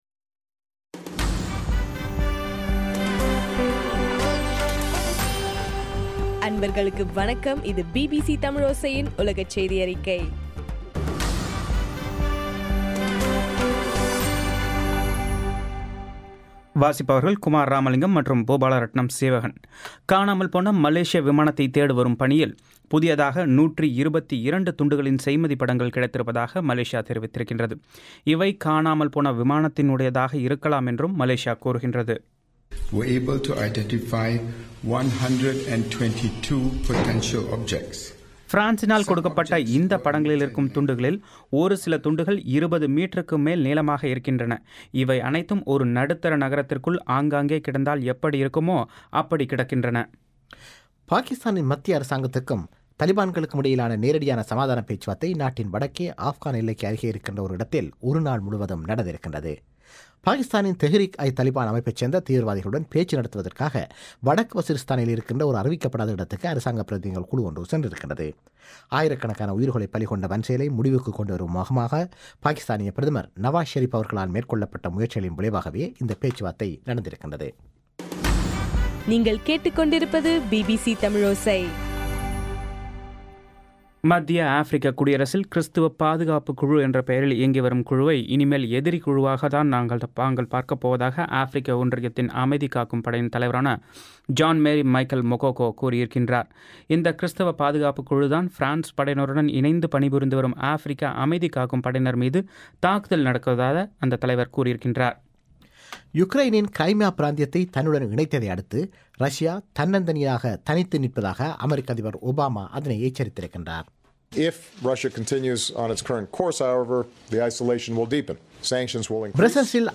மார்ச் 26 - பிபிசியின் உலகச் செய்திகள்